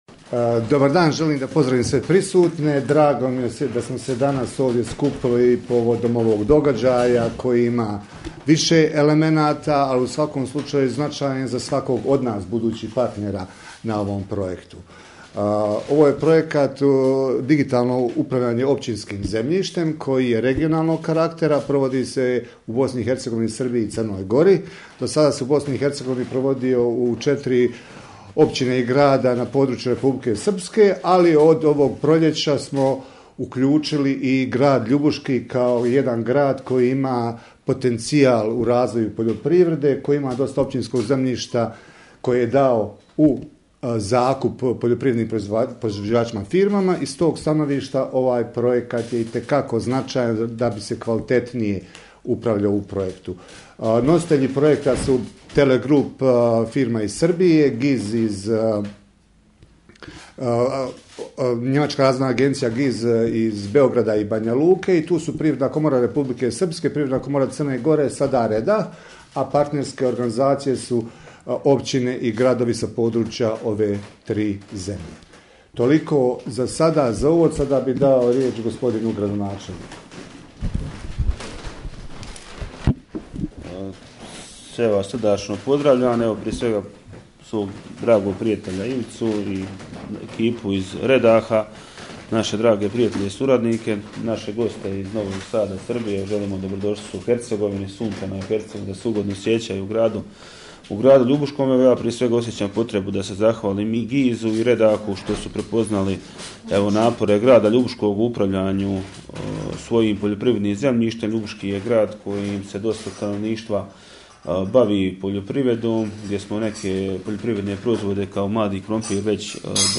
Gradonačelnik Ljubuškog Vedran Markotić izrazio je zadovoljstvo što se ovaj projekt provodi u Ljubuškom jer će kako je istaknuo doprinijeti poboljšanju kvalitete upravljanja zemljištem.